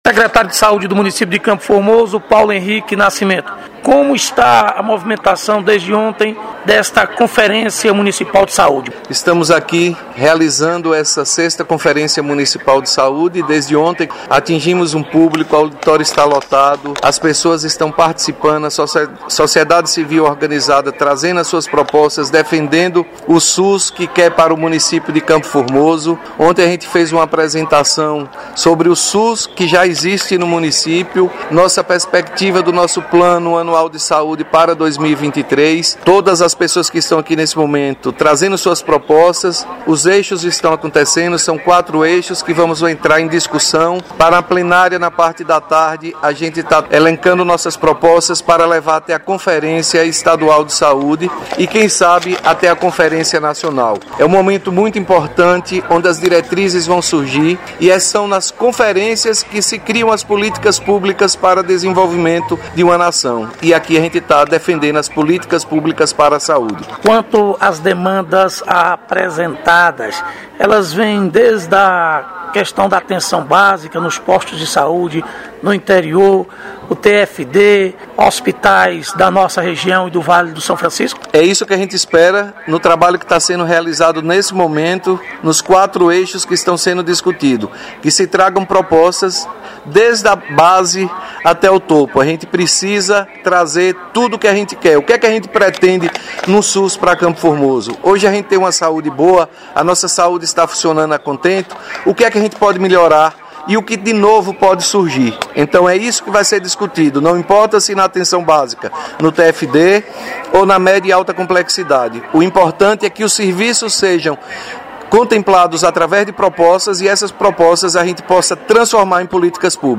É um momento muito importante onde as diretrizes vão surgir e são nas conferências em que se criam as políticas públicas de desenvolvimento de uma nação”, afirmou o secretário de saúde Paulo Henrique em entrevista à reportagem da 98 FM.